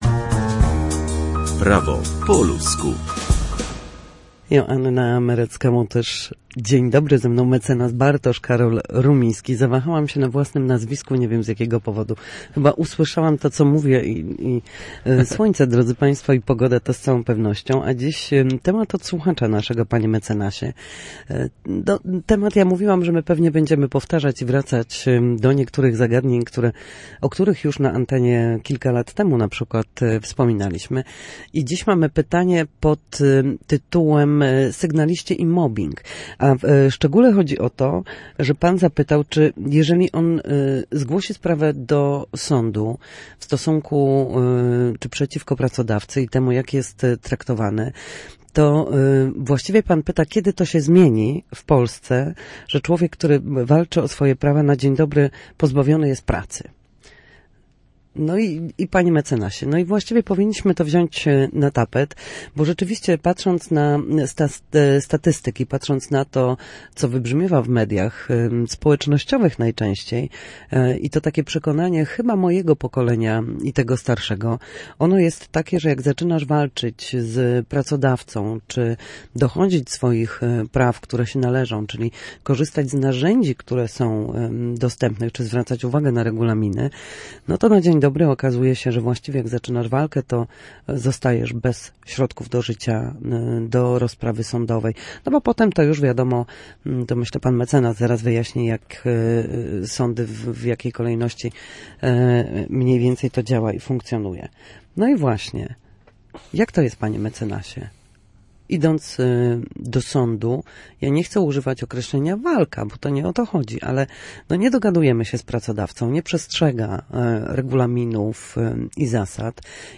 W każdy wtorek o godzinie 13:40 na antenie Studia Słupsk przybliżamy Państwu meandry prawa. Nasi goście – prawnicy – odpowiadają na jedno pytanie dotyczące zachowania w sądzie lub podstawowych zagadnień prawniczych.